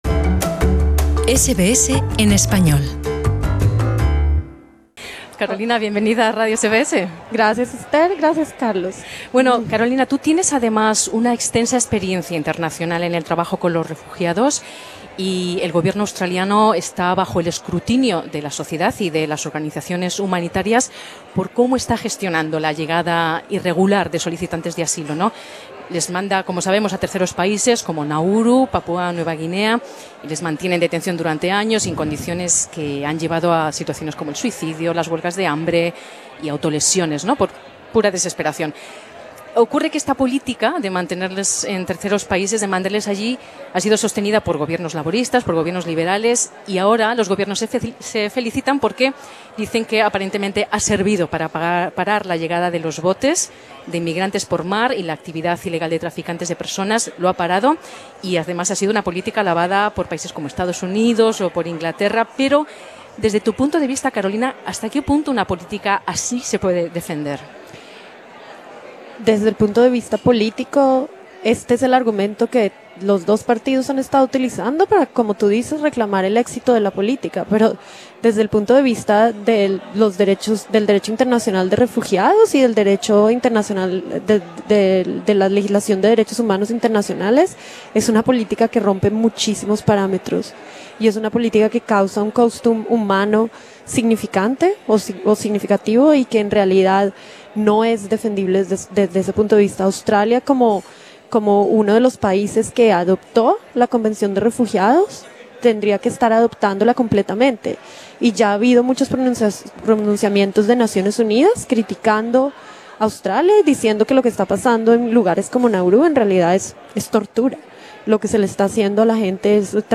Profesionales de organizaciones de asistencia a los refugiados y a las personas que sufren trauma se felicitan por la decisión del ejecutivo de Morrison de evacuar a los niños de Nauru pero alertan del daño profundo que ha sido infligido a estas personas y de la falta de ayudas del gobierno para atenderles en Australia. Escucha nuestro podcast con las entrevistas completas.